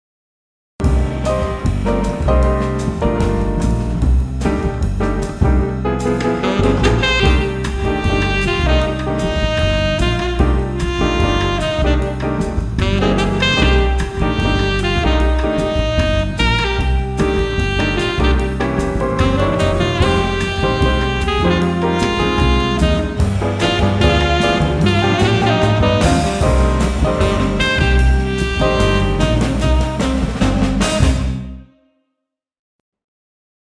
Room Effect Samples
Lexicon PCM96
Preset - Live Room 1
Room_PCM96_Live_Room1.mp3